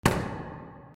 / H｜バトル・武器・破壊 / H-05 ｜銃火器
25 スナイパーライフルの銃声 03